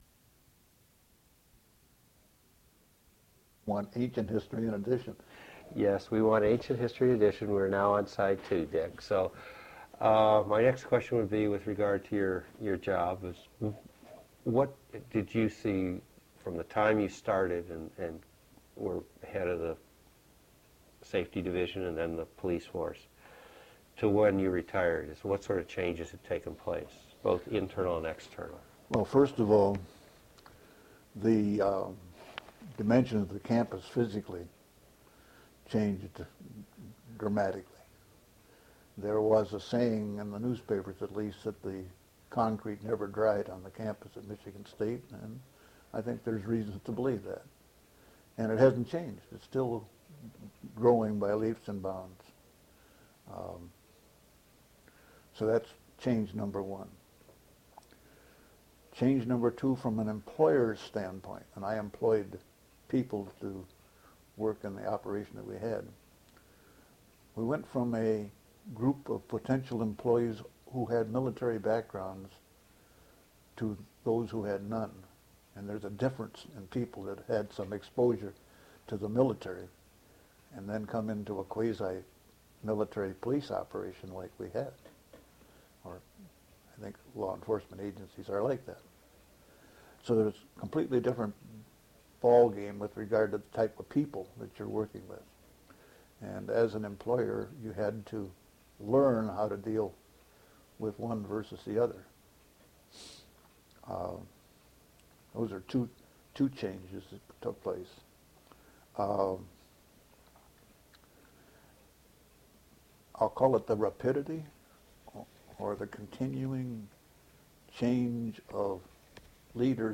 Interview
Original Format: Audiocassettes